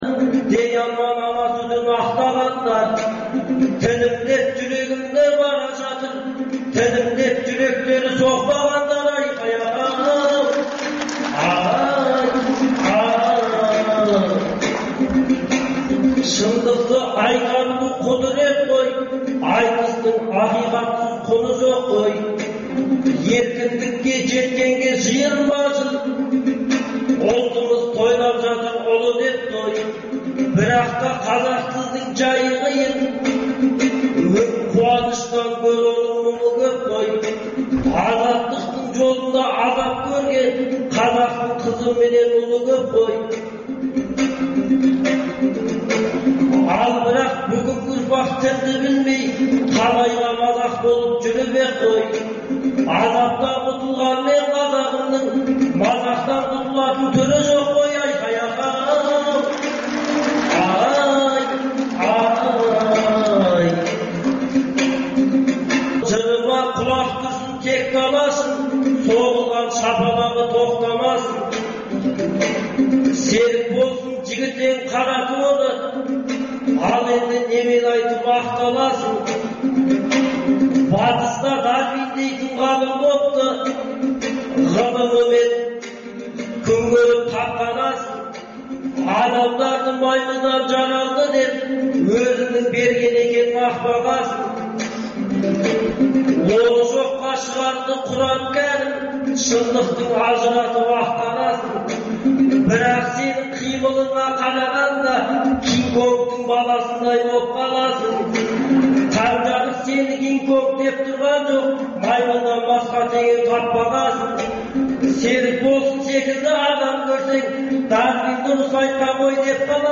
Айтыс - Азаттықта
Қазақстанда әр уақытта өткізілетін ақындар айтысының толық нұсқасын ұсынамыз.